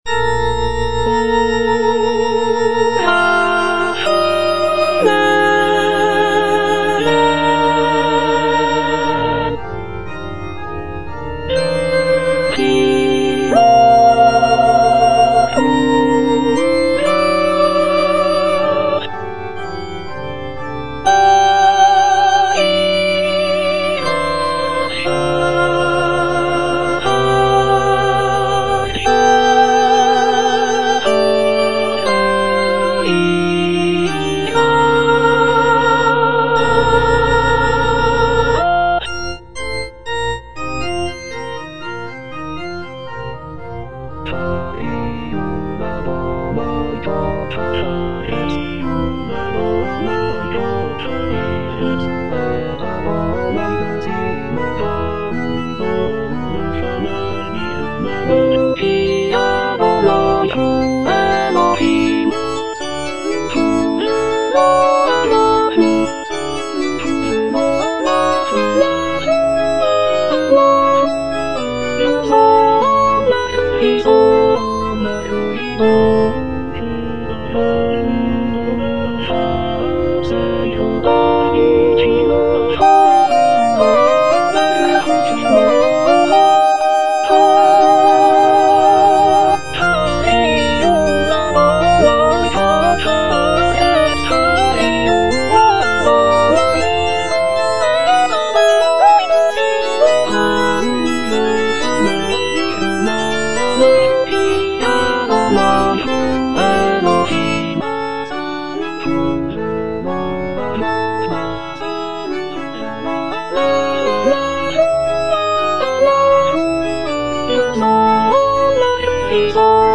soprano I) (Emphasised voice and other voices) Ads stop